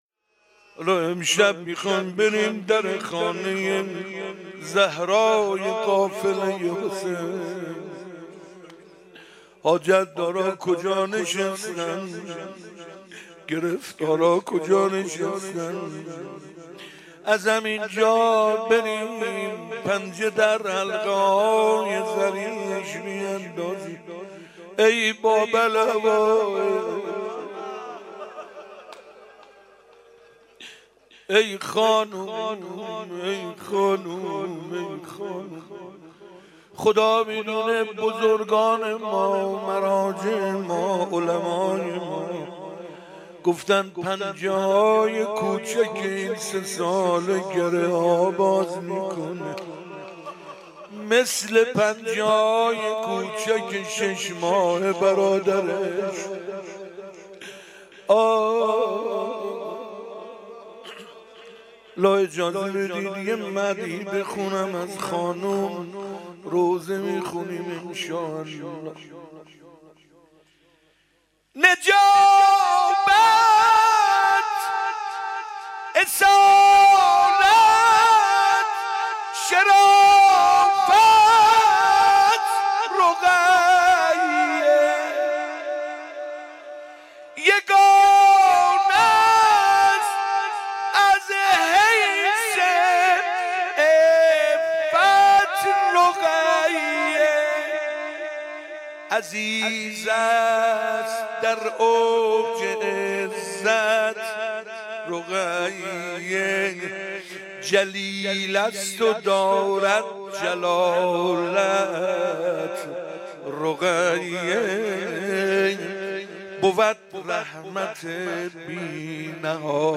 روضه خوانی